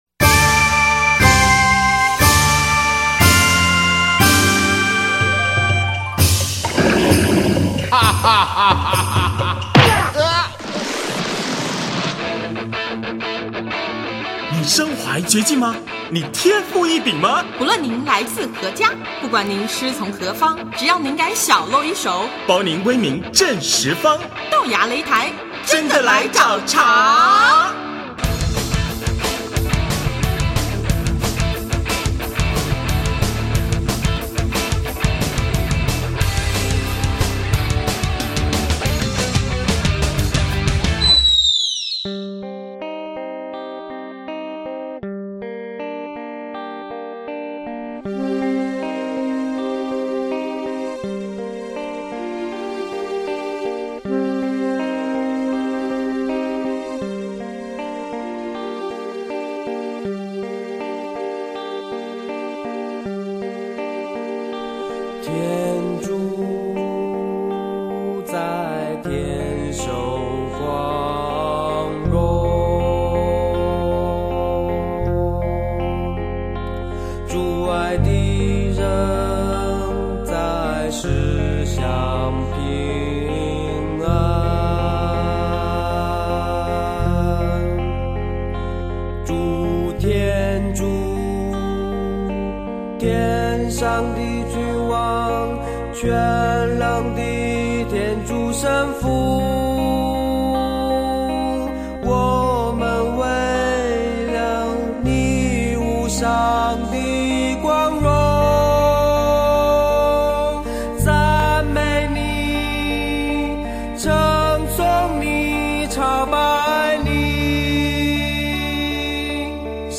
【豆芽擂台】217|专访望乐团(二)：快跑进棚